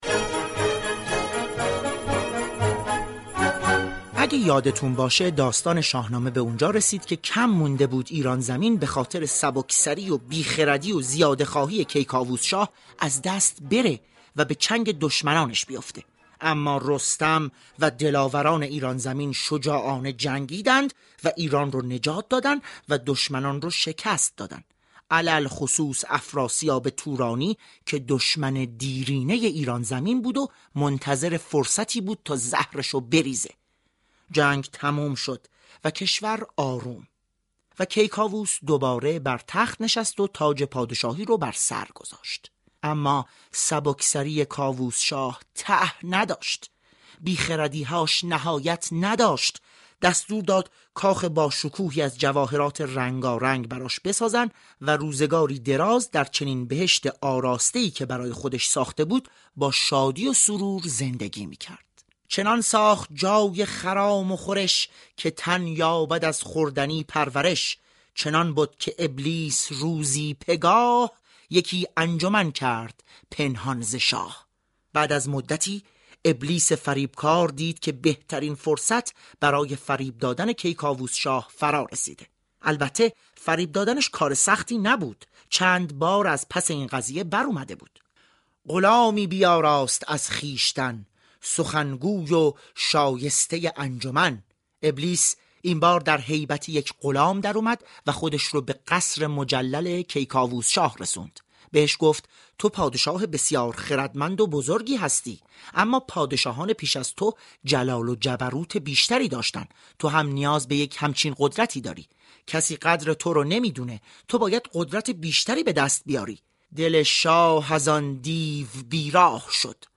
رادیو صبا روز چهارشنبه در برنامه «چنین گفت» ساده و روان این داستان شاهنامه را تقدیم مخاطبان می كند.